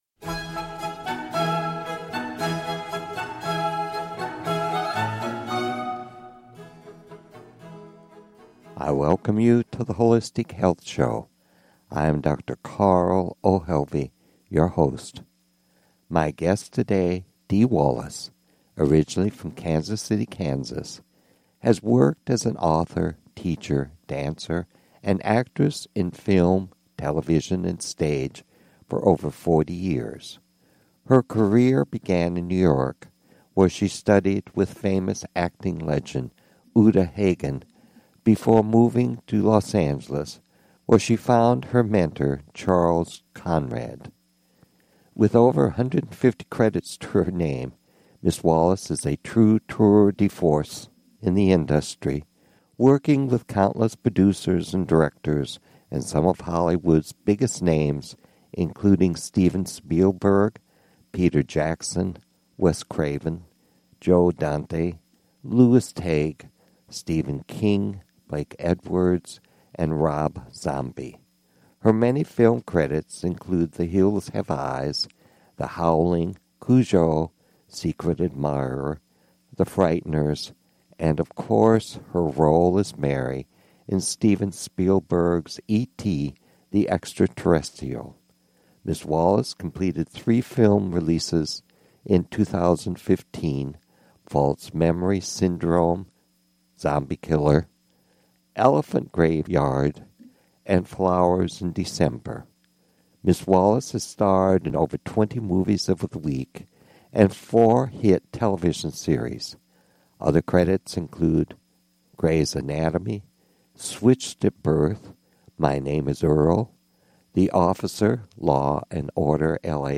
Guest, Dee Wallace